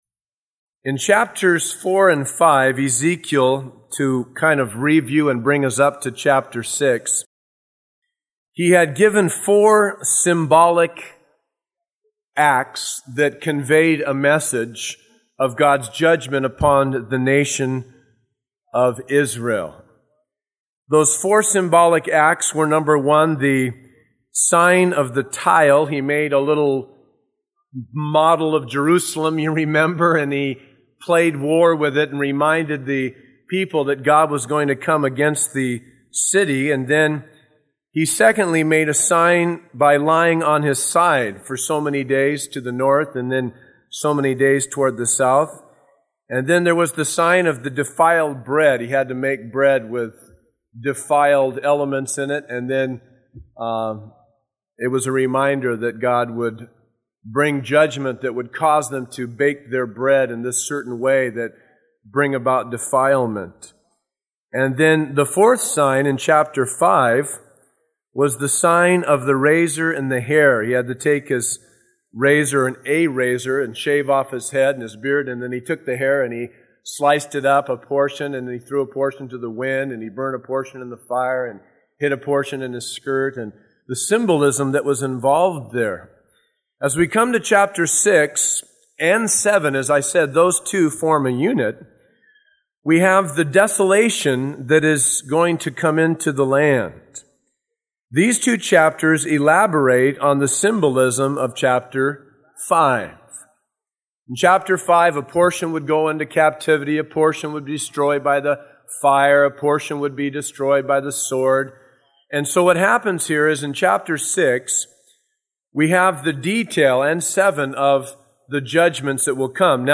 taught at Calvary Chapel San Bernardino from August 1998